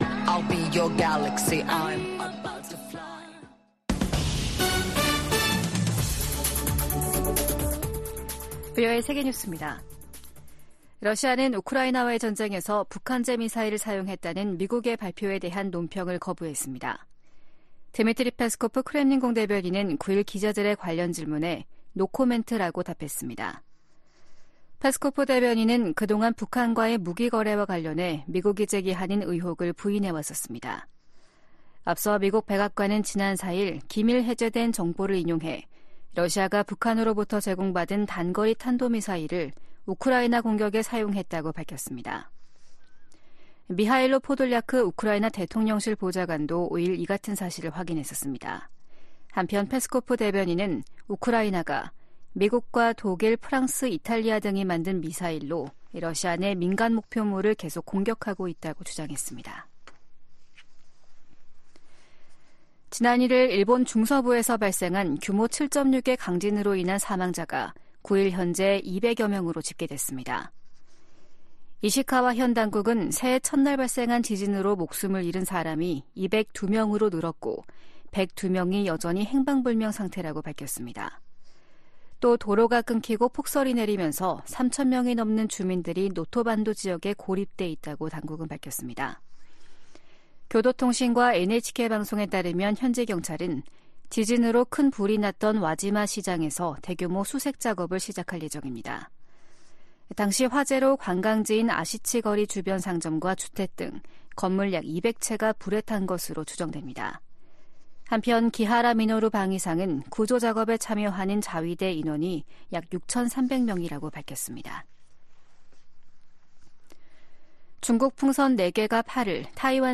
VOA 한국어 아침 뉴스 프로그램 '워싱턴 뉴스 광장' 2024년 1월 10일 방송입니다. 미국이 북한에 도발 자제와 외교적 해결을 촉구하는 한편 한국에 확고한 방위 공약을 거듭 확인했습니다. 북한이 포격 도발을 한 것은 미한일 3국 협력 불만 표출과 총선을 앞둔 한국을 혼란시키려는 것으로 미 전문가들은 분석했습니다. 북한이 러시아에 첨단 단거리 미사일(SRBM)까지 넘긴 것으로 알려지면서 군사협력이 상당히 높은 수준에서 이뤄질 가능성이 제기되고 있습니다.